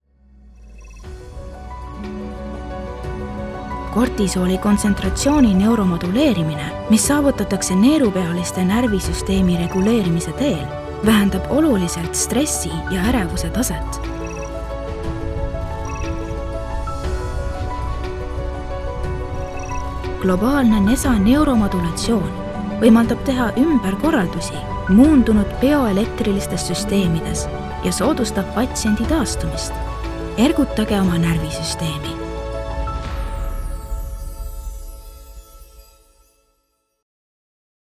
Medical Narration
Conversational, young, mature, raspy, seductive, condescending, friendly, cool, warm, softspoken, calm, soothing, motherly, whispery, breathy, monotone, dramatic, funny, mysterious, emotional, youthful, low, genuine, authentic, neutral, intense.